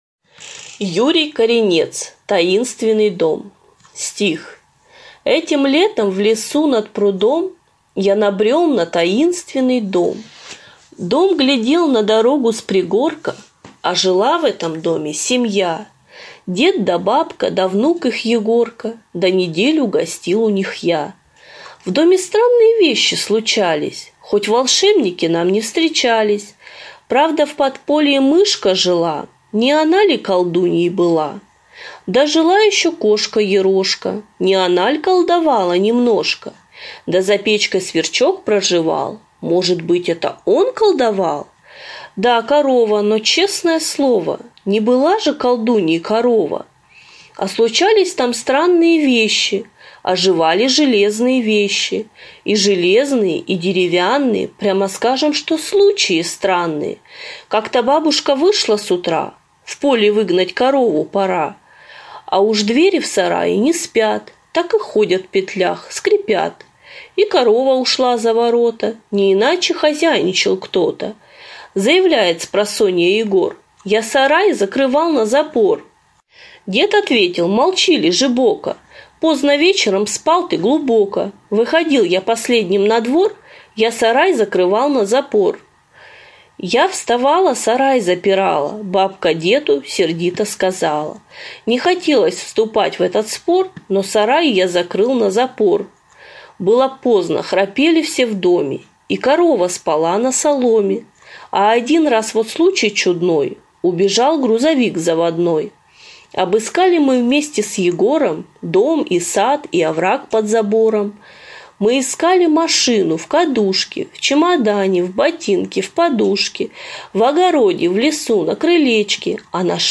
На данной странице вы можете слушать онлайн бесплатно и скачать аудиокнигу "Таинственный дом" писателя Юрий Коринец.